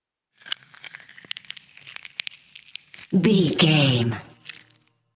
Creature eating flesh peel juicy
Sound Effects
Atonal
scary
ominous
disturbing
horror